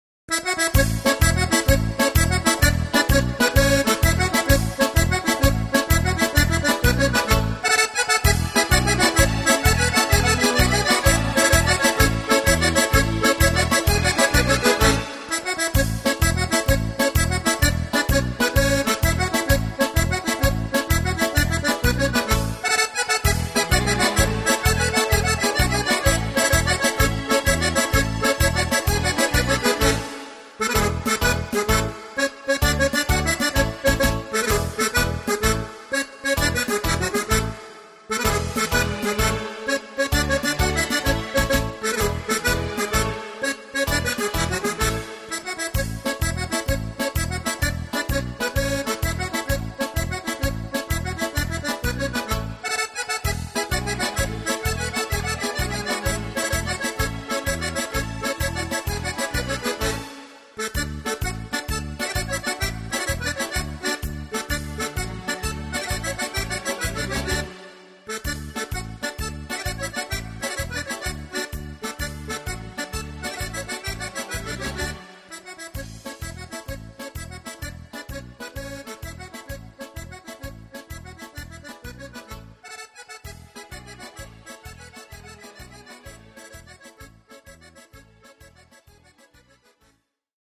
Quadriglia